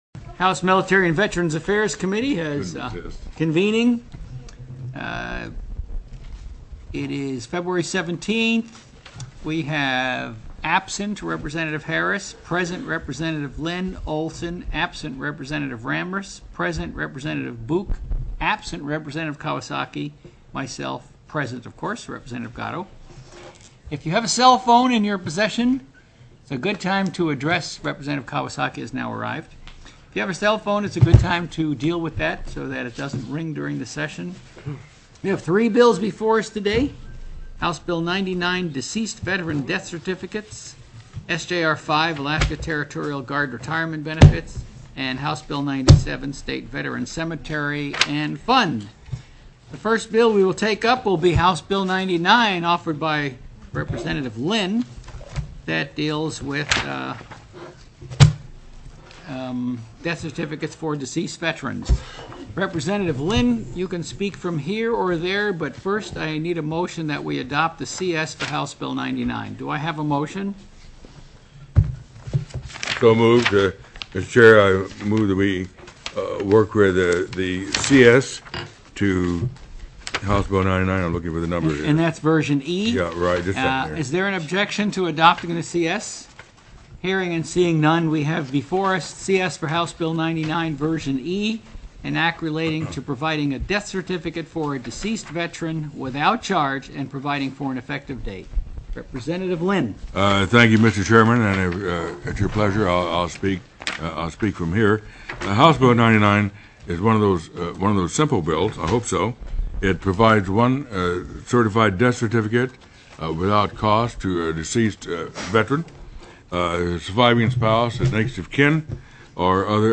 HB 99 DECEASED VETERAN DEATH CERTIFICATE/HONOR TELECONFERENCED
REPRESENTATIVE LYNN, speaking as chair of the House State Affairs Standing Committee, sponsor of HB 99 , explained that HB 99 provides a certified death certificate, without cost, to the surviving spouse, next of kin, or other eligible relative of a deceased veteran.